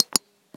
check-on.wav